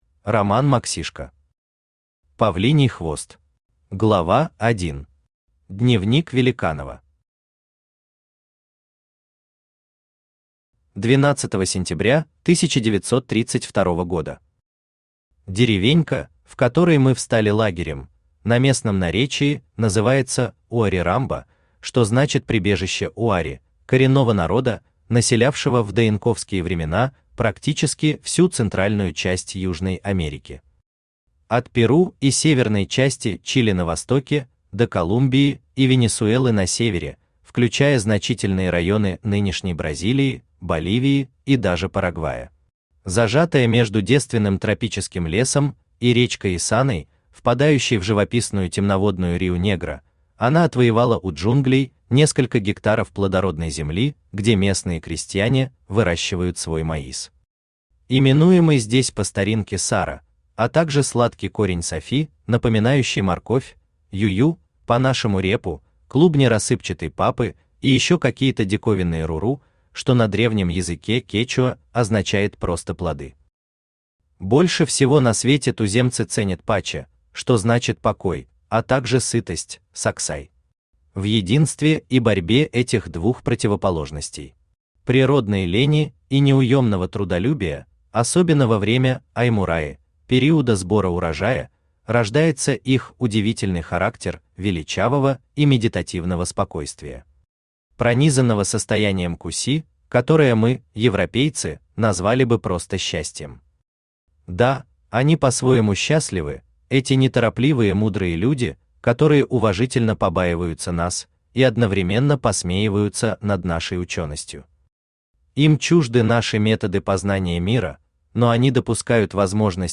Аудиокнига Павлиний хвост | Библиотека аудиокниг
Aудиокнига Павлиний хвост Автор Роман Максишко Читает аудиокнигу Авточтец ЛитРес.